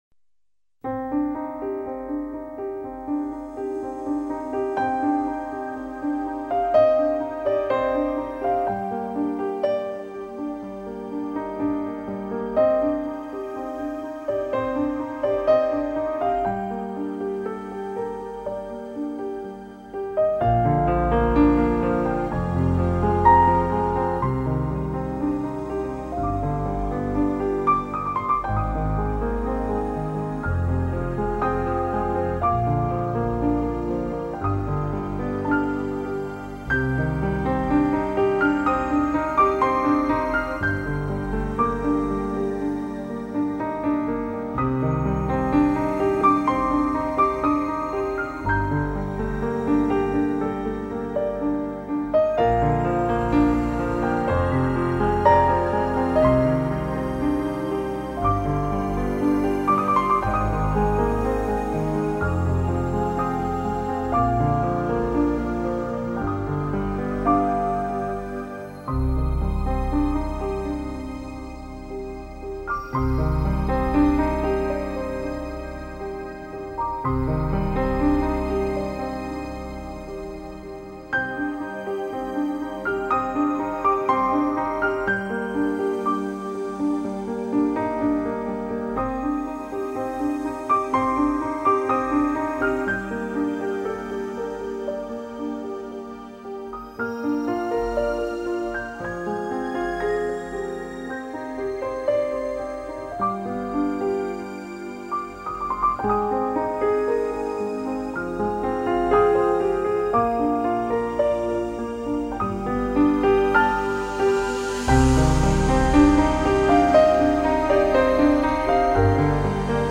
سلام اهنگ بیکلام 1